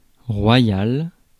Ääntäminen
Synonyymit trianon Ääntäminen France: IPA: /ʁwa.jal/ Haettu sana löytyi näillä lähdekielillä: ranska Käännös Adjektiivit 1. kuninglik Muut/tuntemattomat 2. majesteetlik Suku: m .